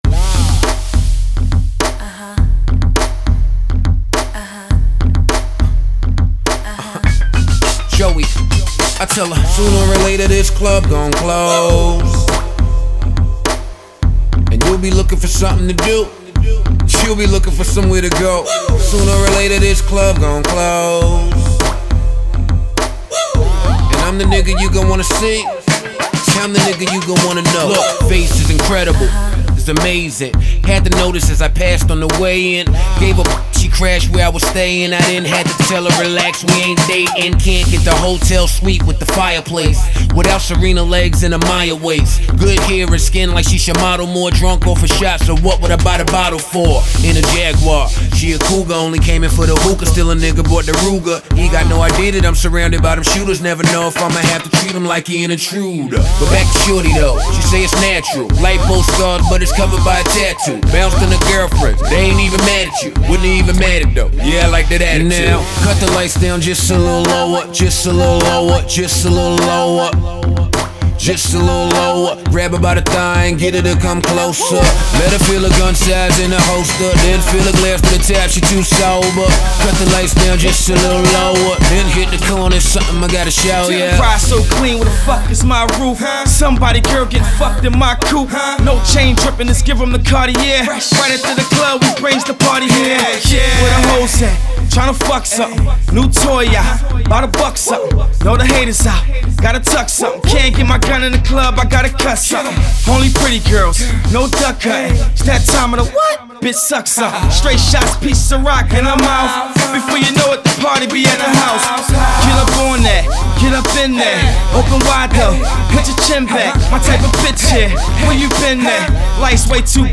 club song